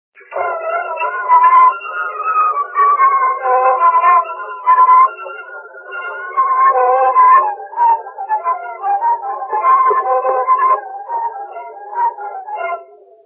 Dallampélda: Hangszeres felvétel